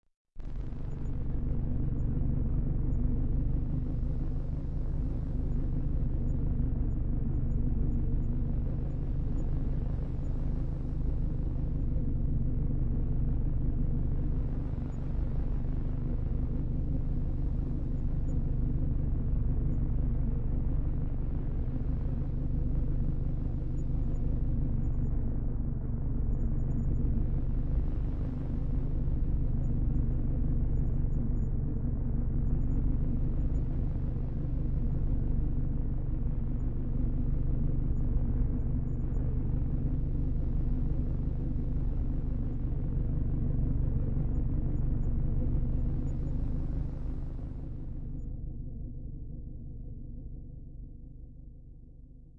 无人机 " 无人机13
描述：用vst仪器制作
Tag: 未来 无人机 驱动器 背景 隆隆声 黑暗 冲动 效果 FX 急诊室 悬停 发动机 飞船 氛围 完善的设计 未来 空间 科幻 电子 音景 环境 噪音 能源 飞船 大气